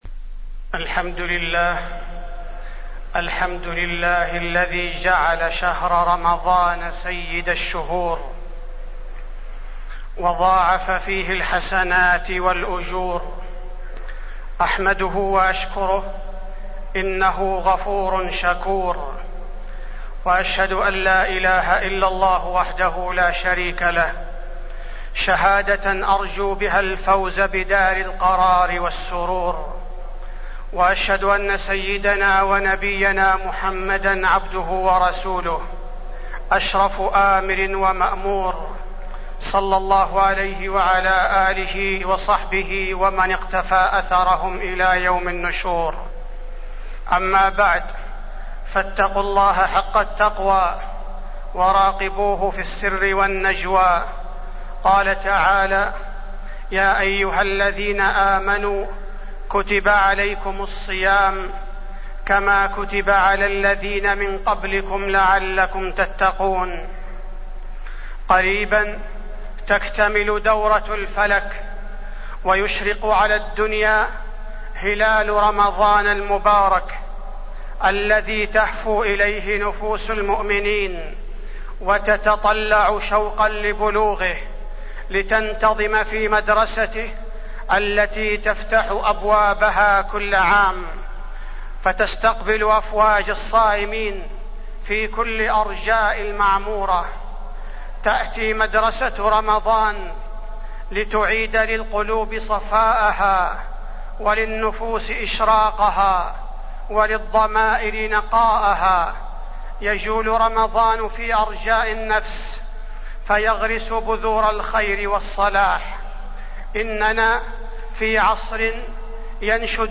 تاريخ النشر ٢٨ شعبان ١٤٢٩ هـ المكان: المسجد النبوي الشيخ: فضيلة الشيخ عبدالباري الثبيتي فضيلة الشيخ عبدالباري الثبيتي استقبال رمضان